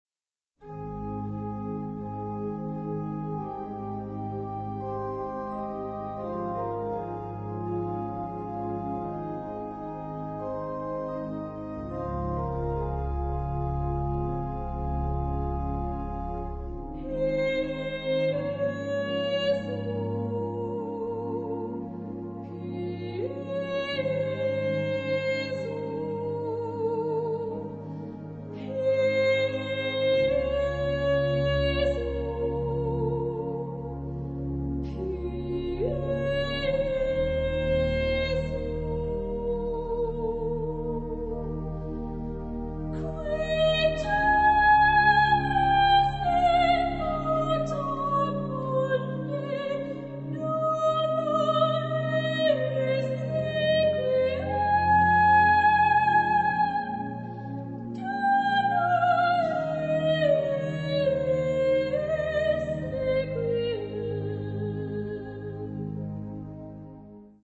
Type de choeur : SATB  (4 voix mixtes )
Instrumentation : Orchestre